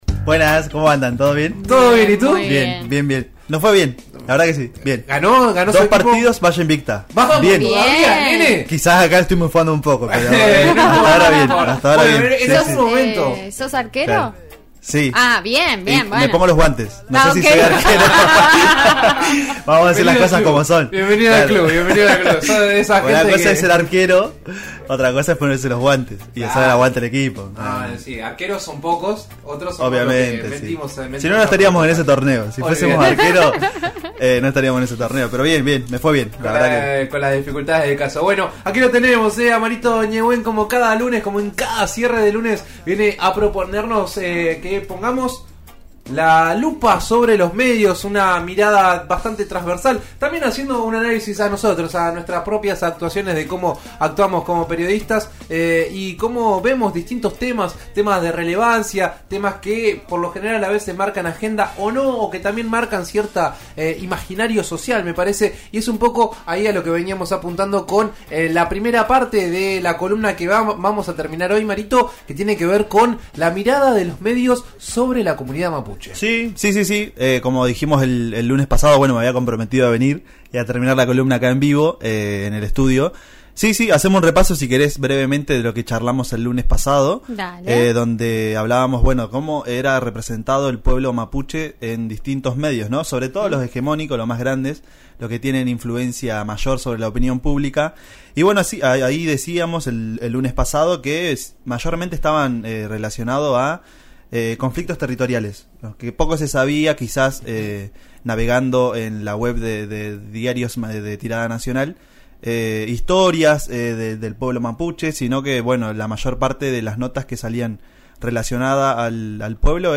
En La Lupa, la columna de En Eso Estamos de RN Radio, conversamos sobre el tema.